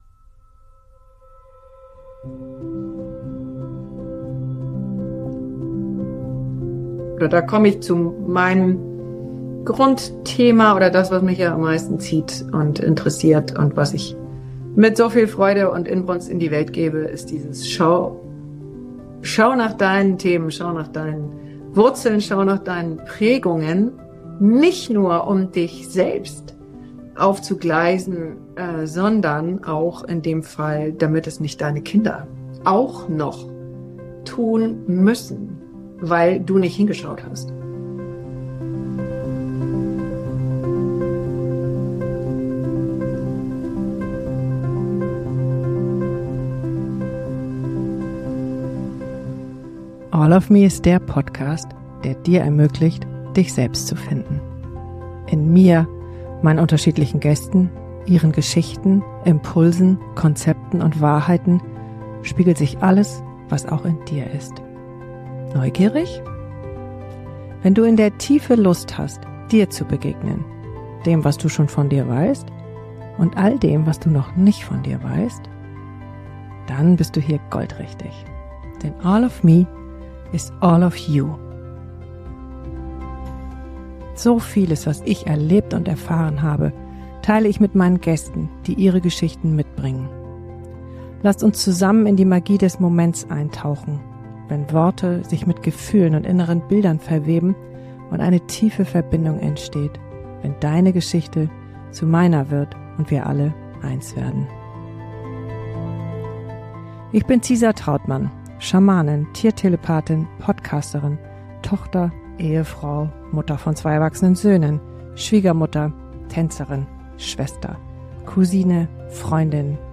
Zeit für eine Solo Folge!